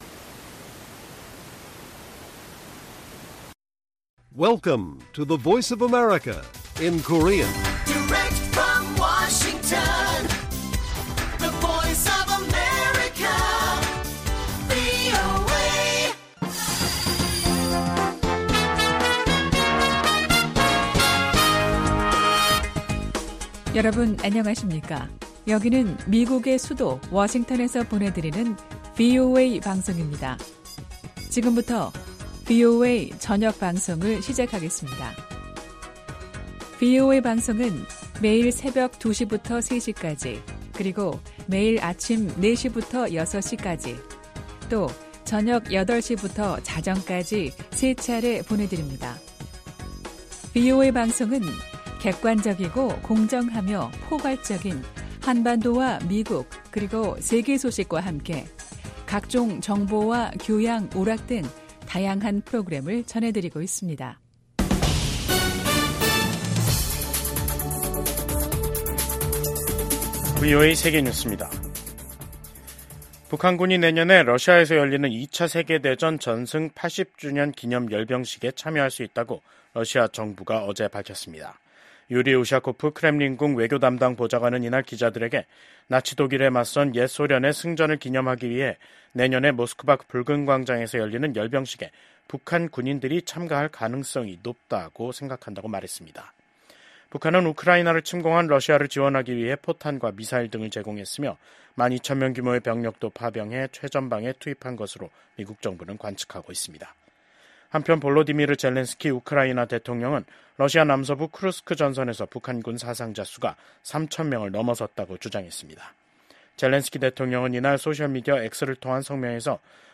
VOA 한국어 간판 뉴스 프로그램 '뉴스 투데이', 2024년 12월 24일 1부 방송입니다. 러시아 당국은 내년 5월 전승절 행사에 북한 군이 참여할 수 있다고 밝혔습니다. 미국과 한국 정부가 최근 한국의 ‘비상계엄’ 사태 등으로 연기됐던 주요 외교, 안보 일정을 재개하기로 합의했습니다.